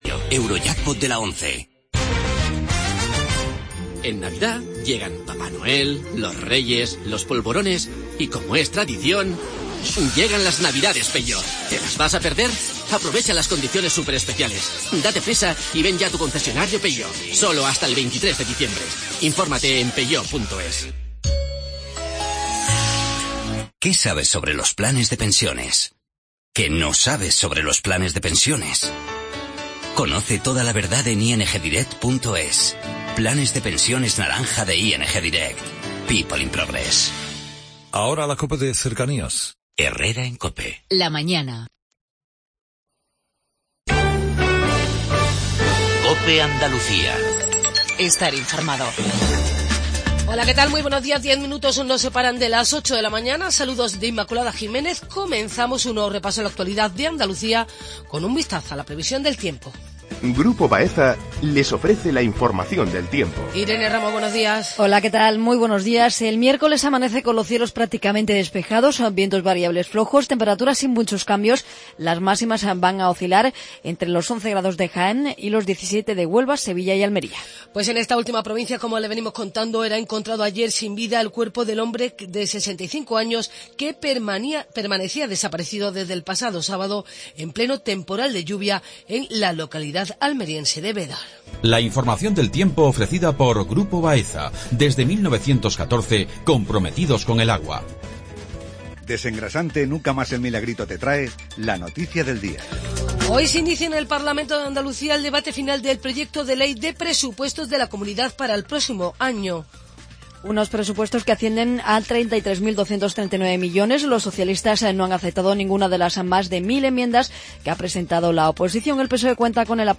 INFORMATIVO REGIONAL/LOCAL MATINAL